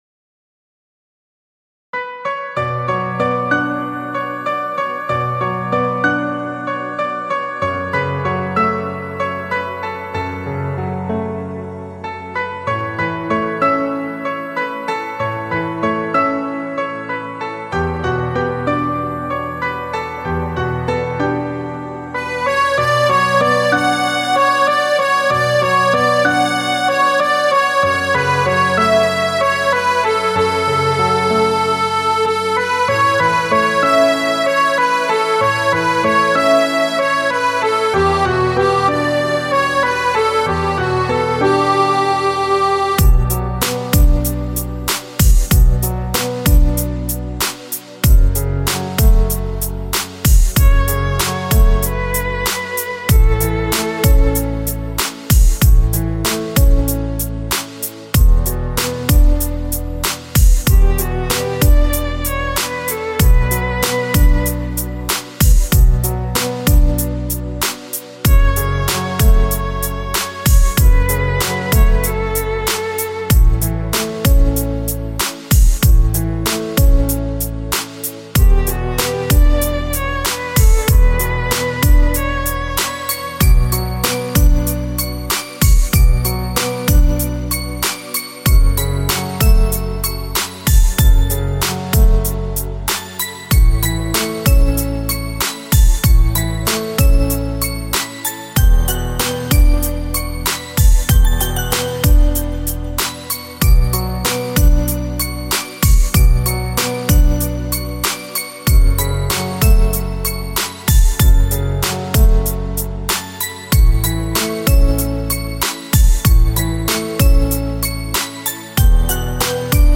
Узбекская музыка
Минусовка